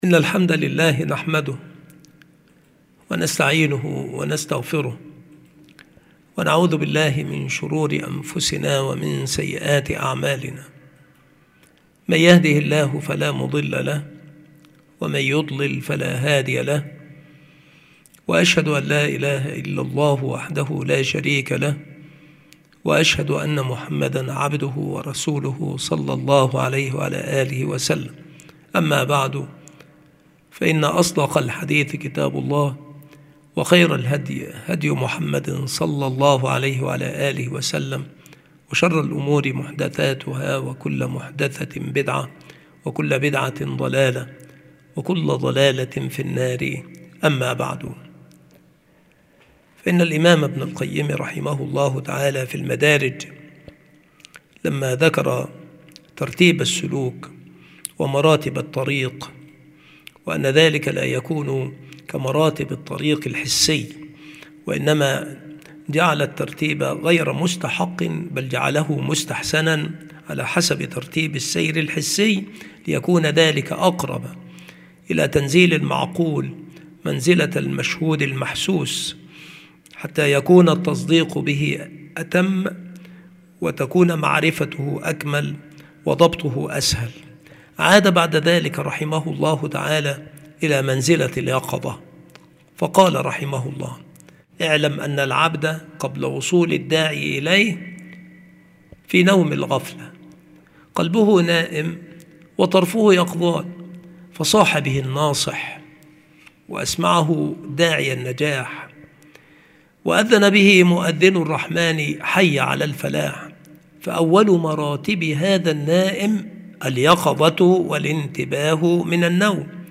مكان إلقاء هذه المحاضرة المكتبة - سبك الأحد - أشمون - محافظة المنوفية - مصر عناصر المحاضرة : منزلة اليقظة.